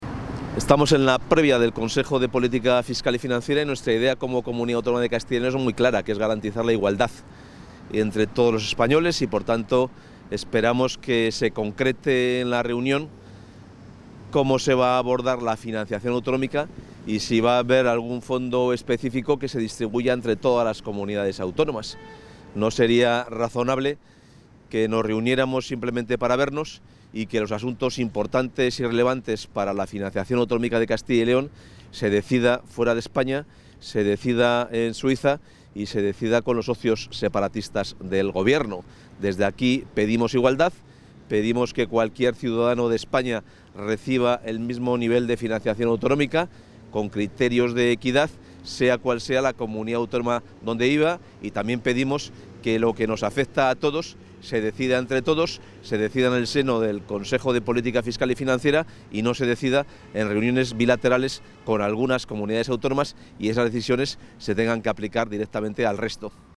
Declaraciones del consejero de Economía y Hacienda previas a la celebración del Consejo de Política Fiscal y Financiera
Se adjunta material audiovisual con las declaraciones del consejero de Economía y Hacienda, Carlos Fernández Carriedo, antes de participar en la reunión del Consejo de Política Fiscal y Financiera.